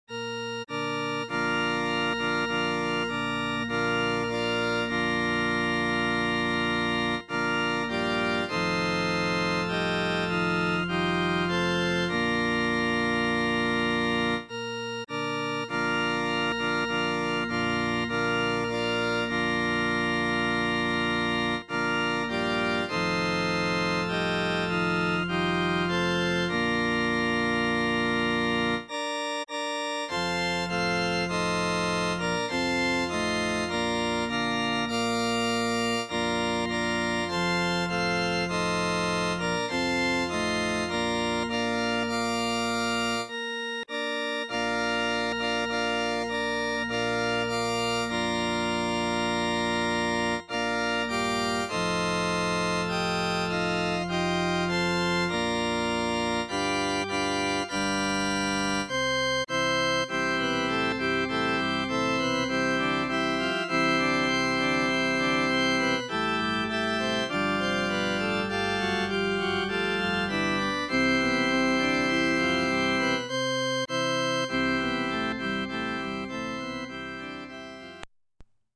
"Appezölle Land, wie bischt du schö"   Orgel
elektroisch